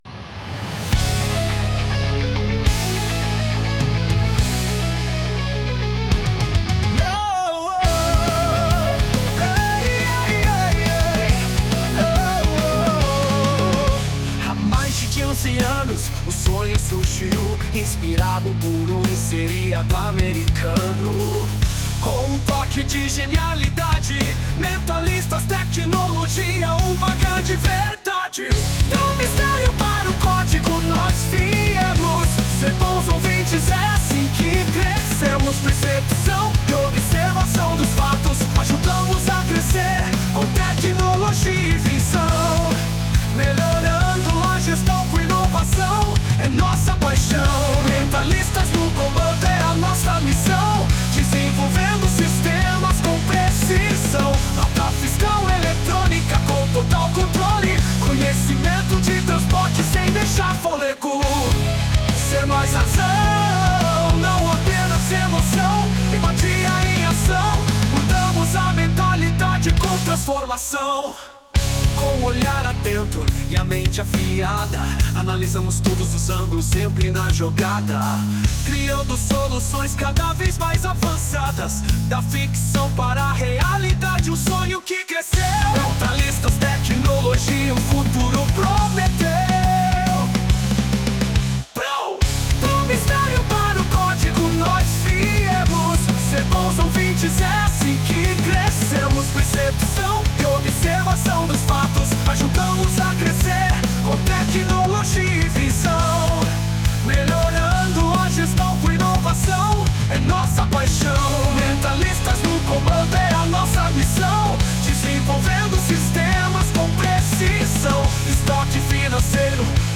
Música criada com IA em homenagem aos 15 anos
por Chatgpt | Suno
mentalistas-song.mp3